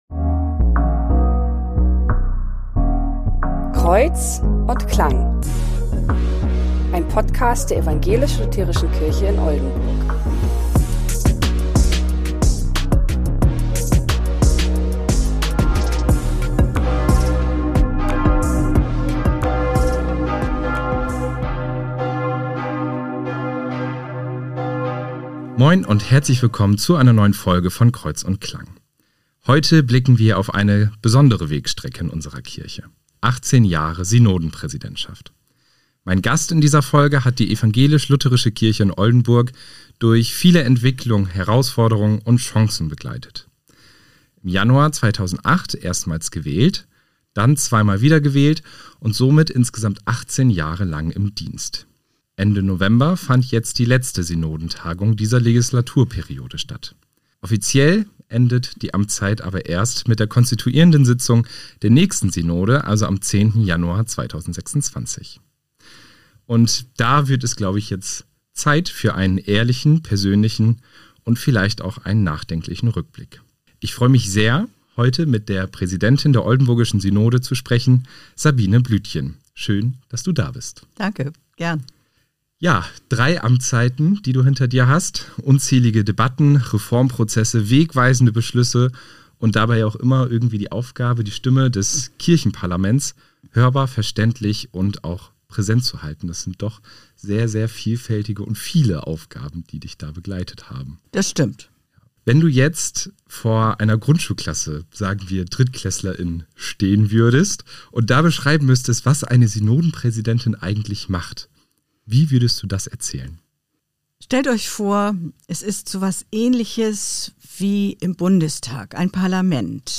Kirchenglocken der St. Secundus Kirche in Schwei im Intro